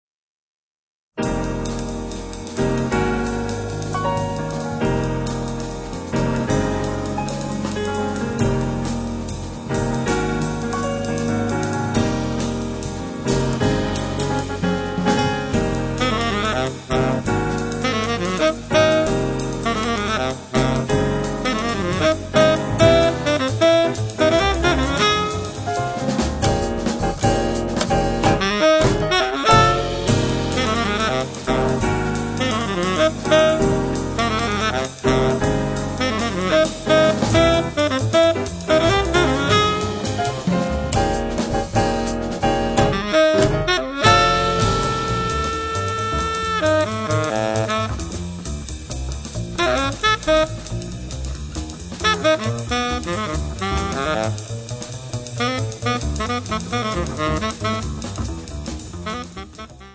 piano
sax tenore e soprano, clarinetto
contrabbasso
batteria